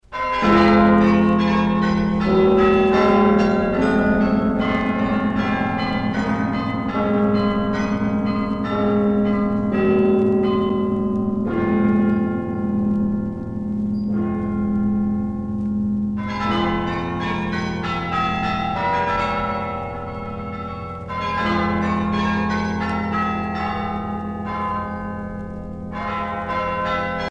carillon de Malines